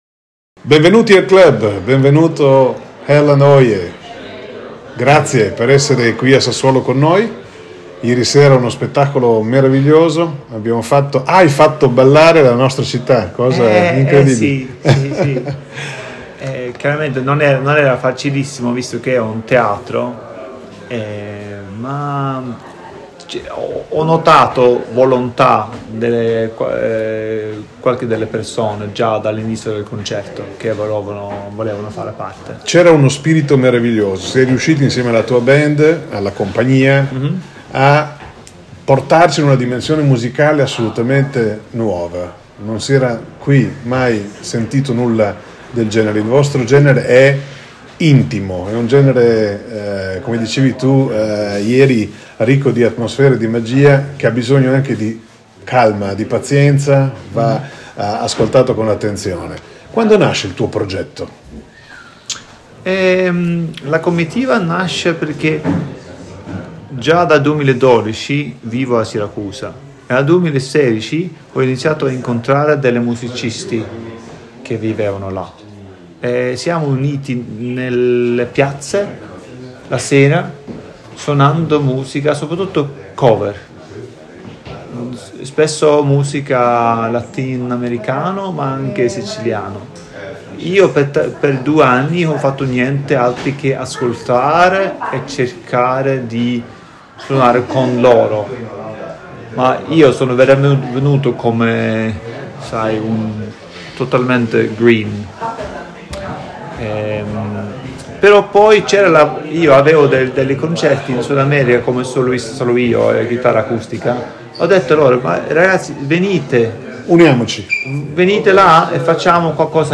Intervista a Erlend Oye – Linea Radio al Clhub di viale XX Settembre a Sassuolo